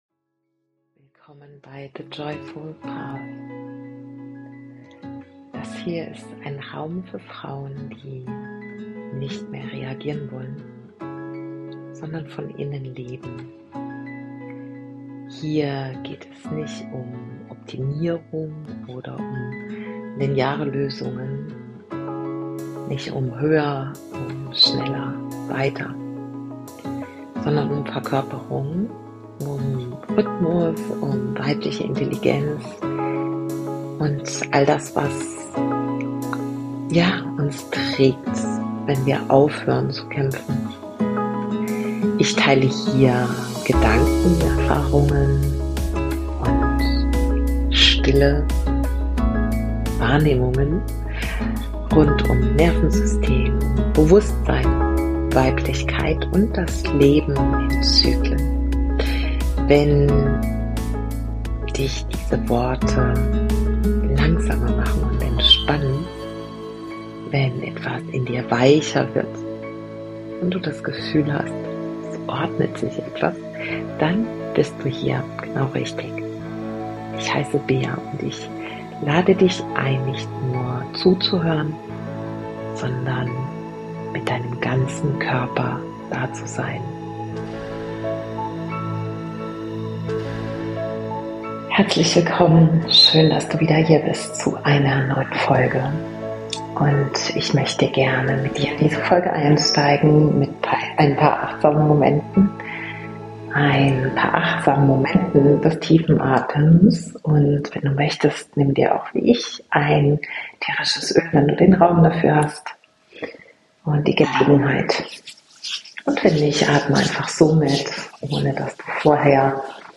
Diese Folge ist am Meer entstanden.